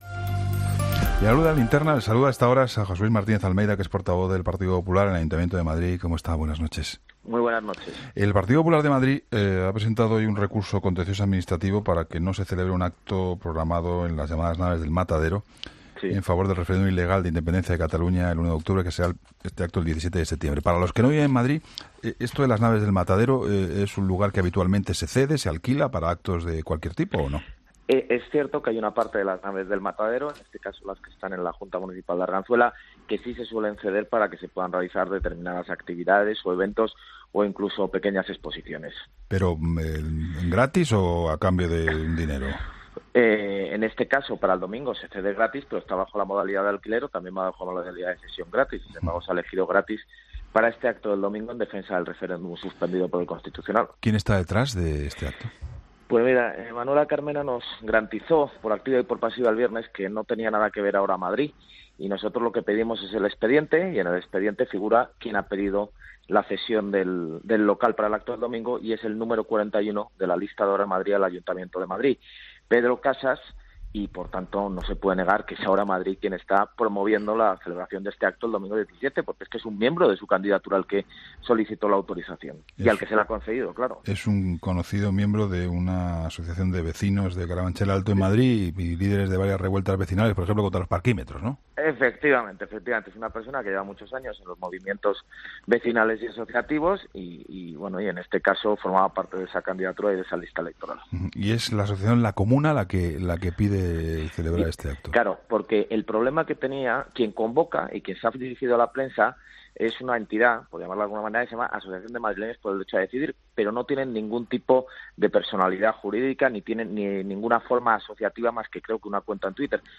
Entrevista José Luis Martínez Almeida, portavoz del PP en el Ayuntamiento de Madrid